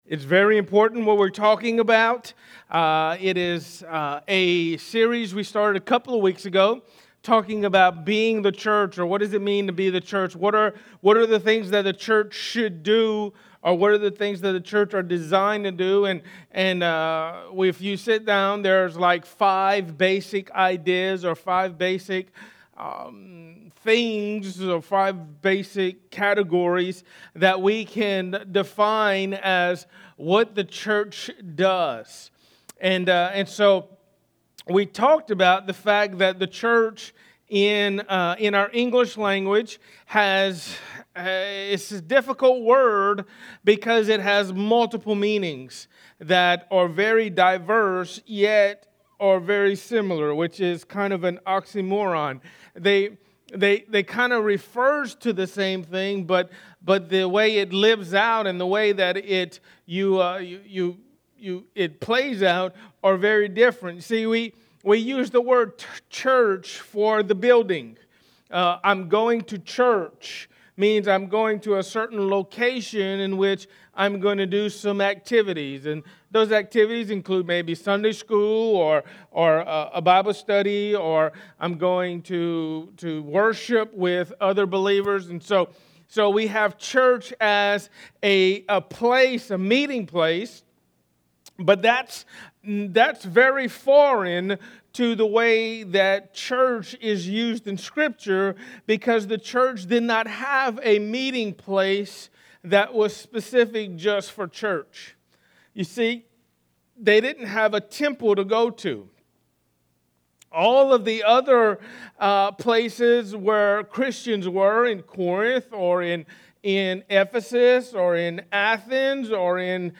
A message from the series "Be The Church."